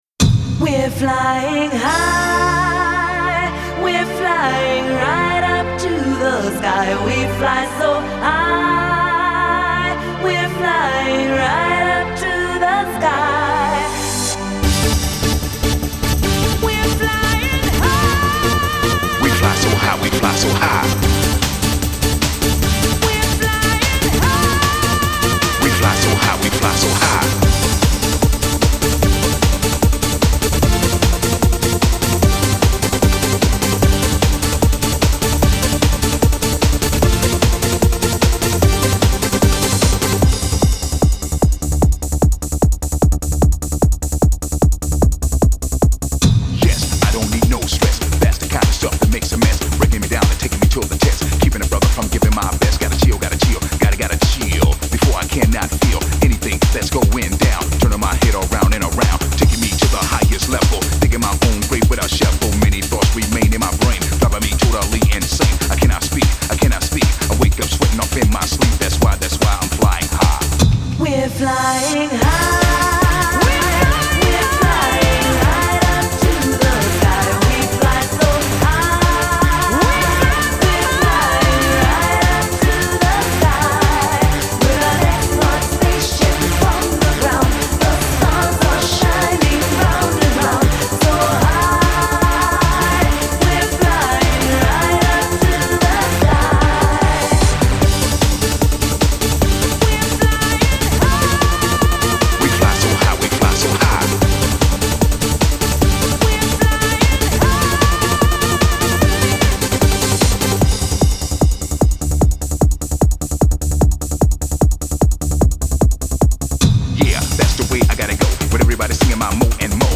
стильная мелодия